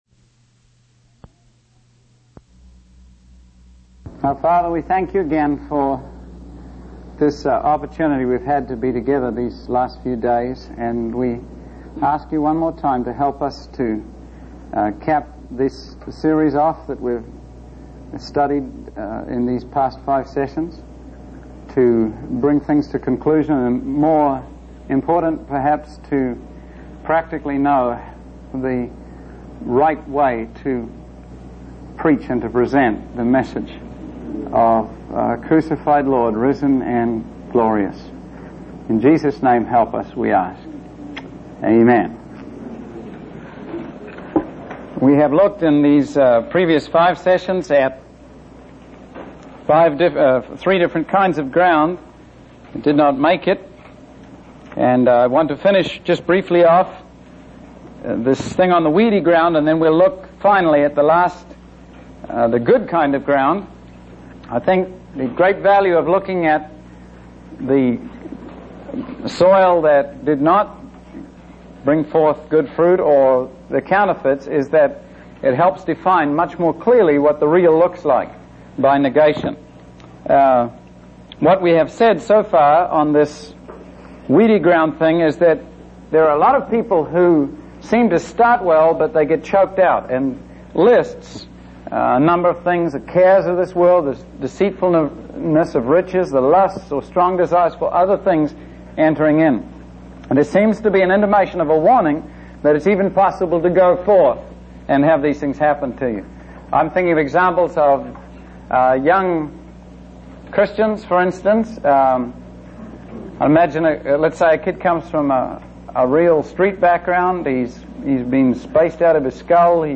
In this sermon, the speaker emphasizes the importance of not rushing through the preaching of the word of God.